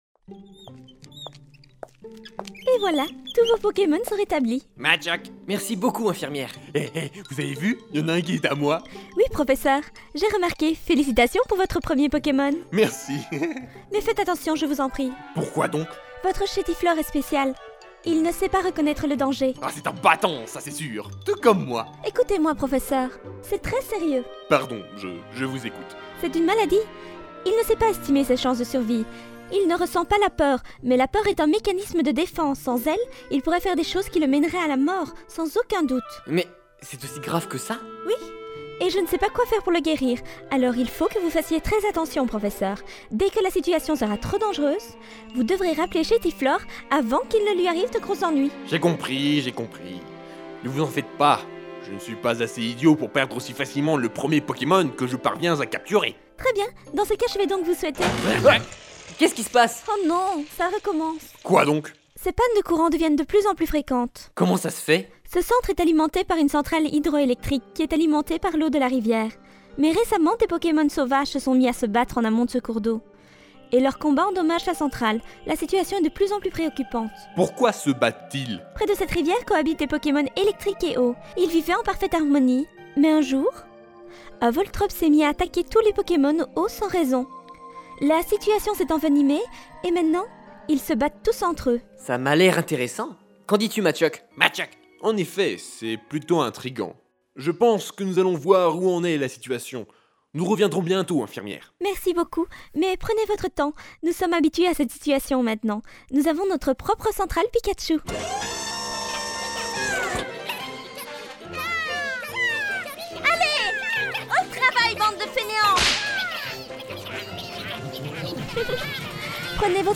cette saga audio relate l'aventure de Grégoire, un jeune dresseur de Pokémon qui découvre la dure réalité du monde des dresseurs de Pokémon! Découvrez aujourd'hui le troisième épisode de la Fansaga mp3 : Conflit au Lac !